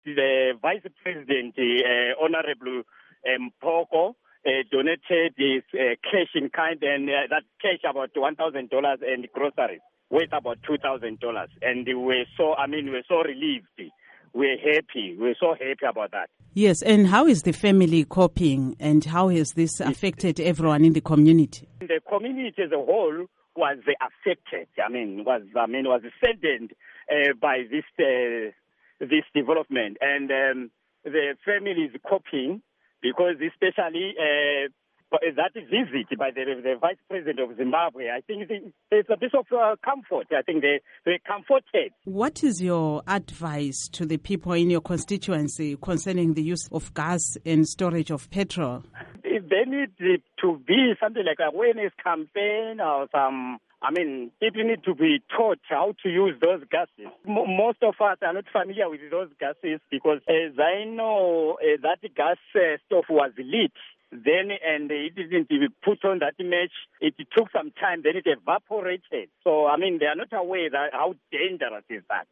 Interview with Saul Mahalima Ncube